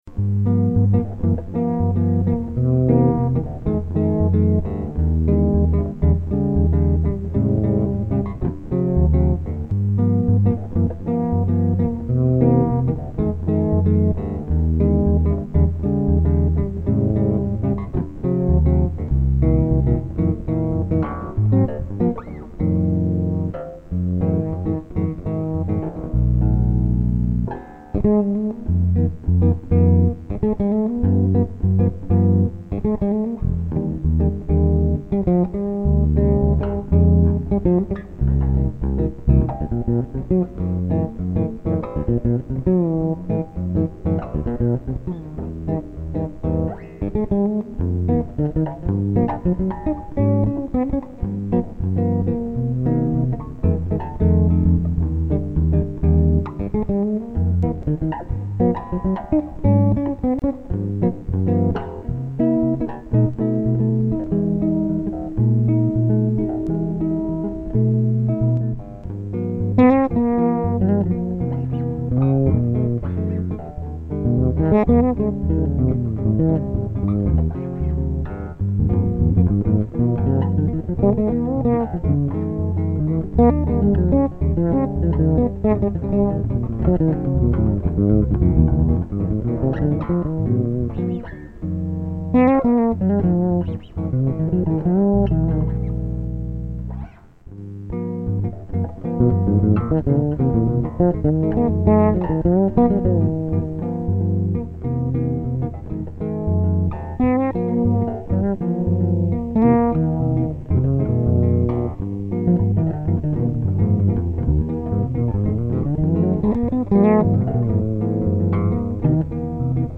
Style JAZZ